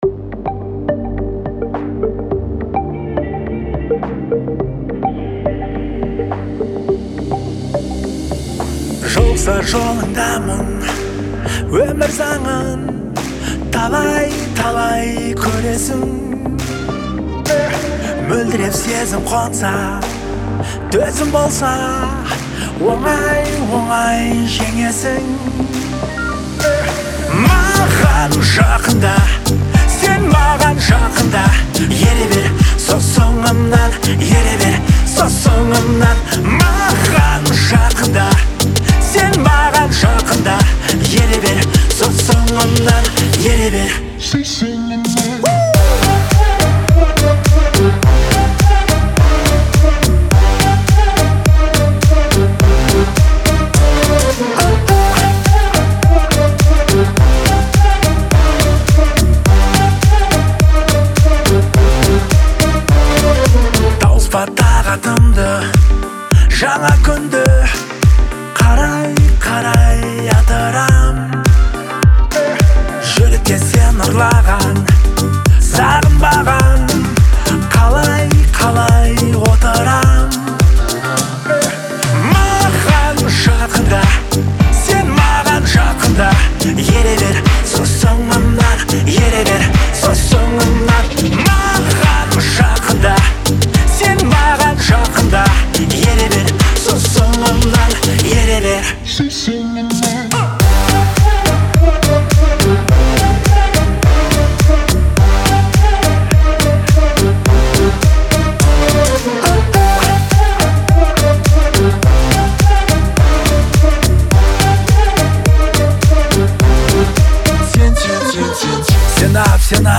это яркая композиция в жанре казахского поп-фолка